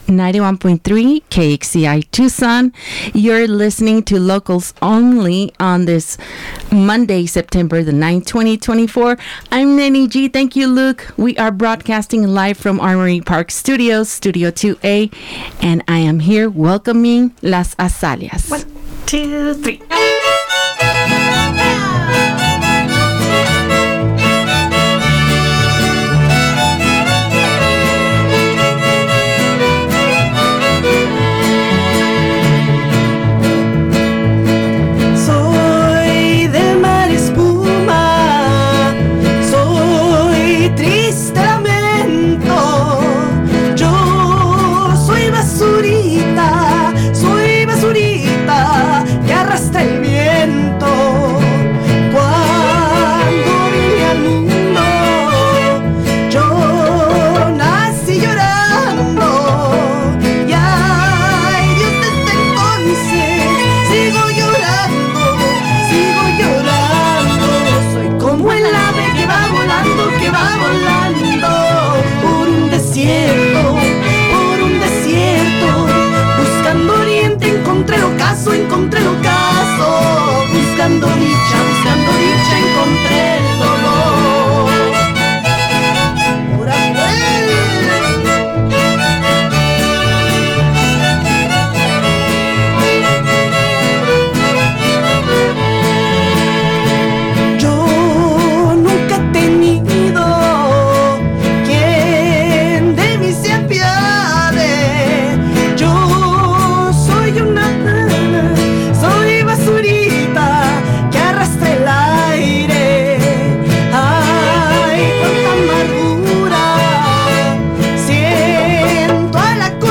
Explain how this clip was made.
live in Studio 2A Full session recording Listen to the live performance + interview here!